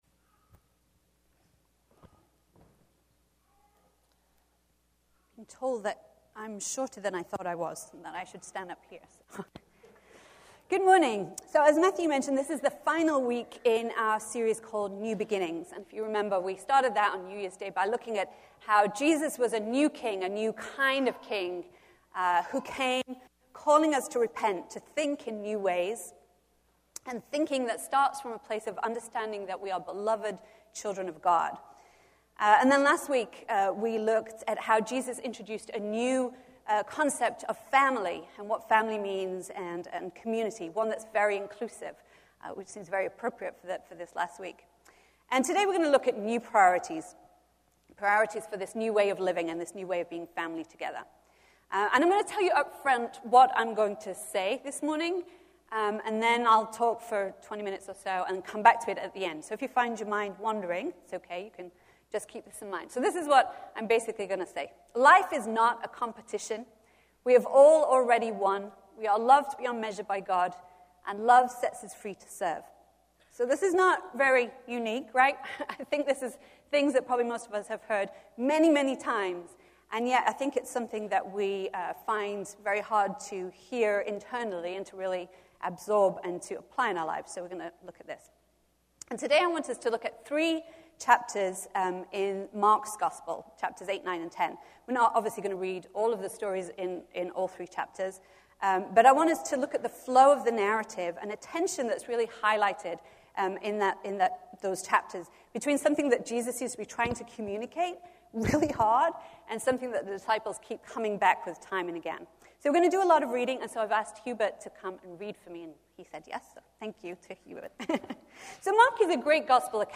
A message from the series "New Beginnings."